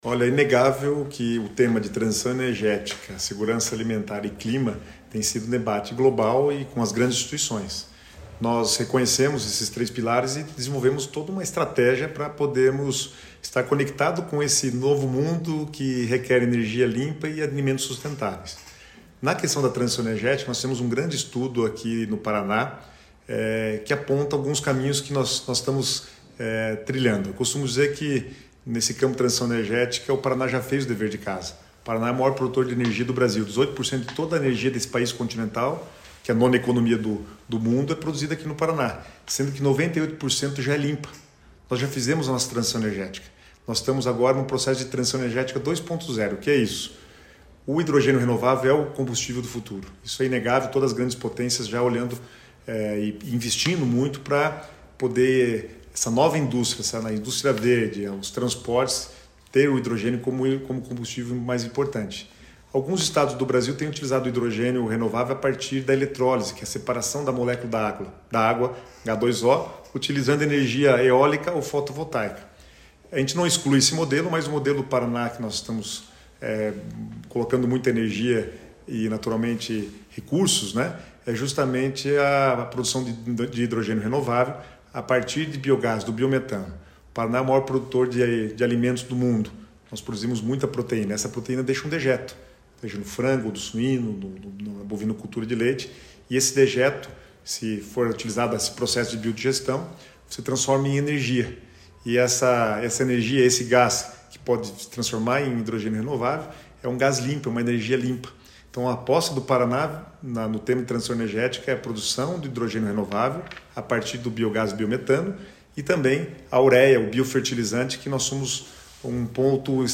Sonora do secretário estadual do Planejamento, Guto Silva, sobre a energia renovável no Paraná